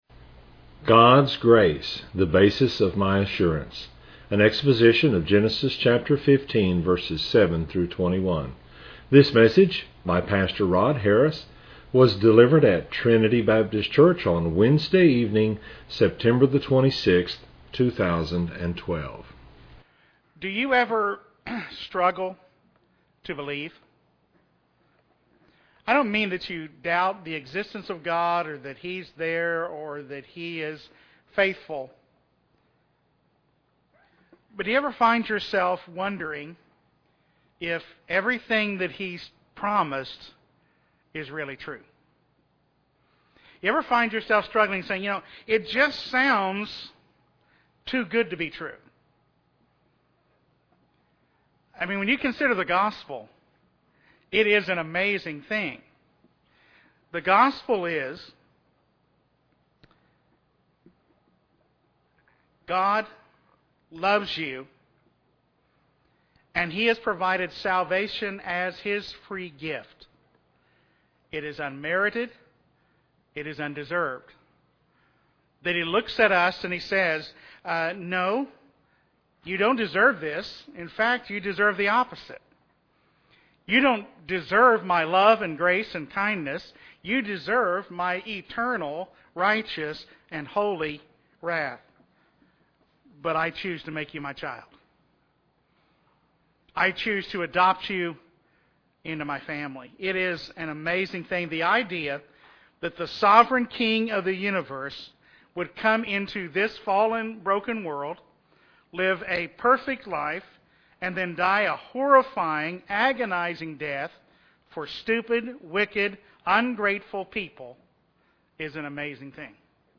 This is an exposition of Genesis 15:7-21.